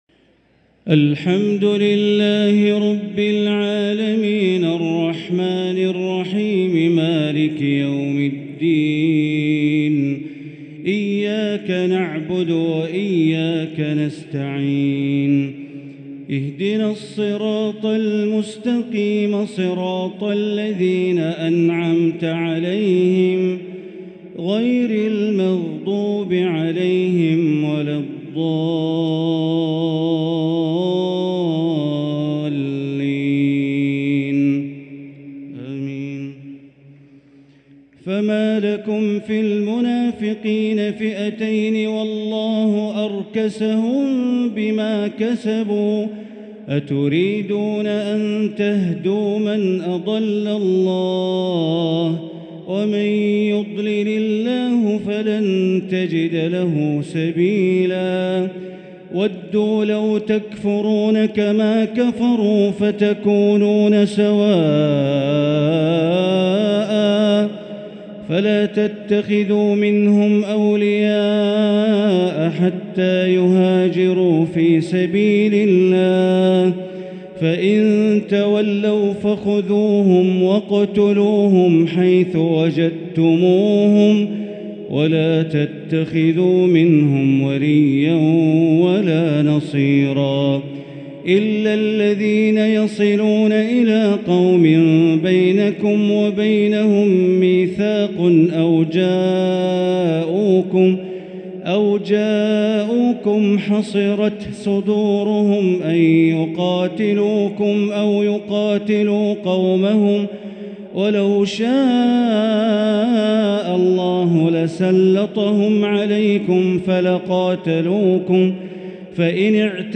تراويح ليلة 7 رمضان 1444هـ من سورة النساء {88-134} Taraweeh 7st night Ramadan 1444H Surah An-Nisaa > تراويح الحرم المكي عام 1444 🕋 > التراويح - تلاوات الحرمين